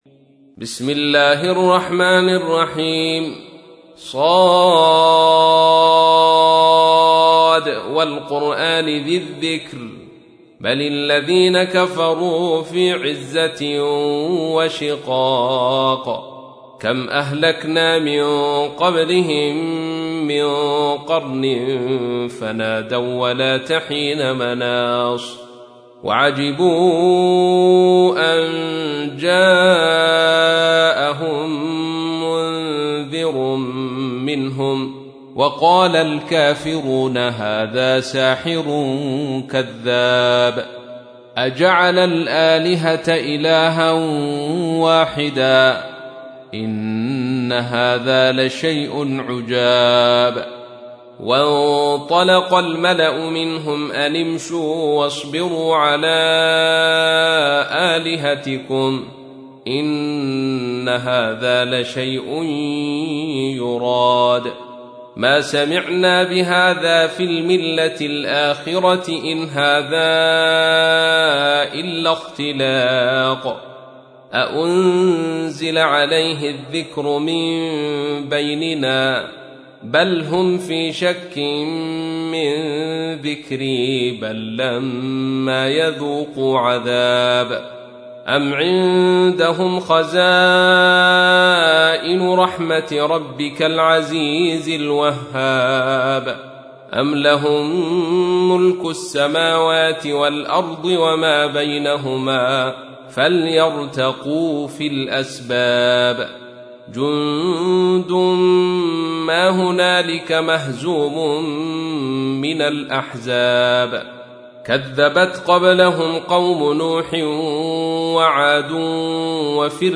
تحميل : 38. سورة ص / القارئ عبد الرشيد صوفي / القرآن الكريم / موقع يا حسين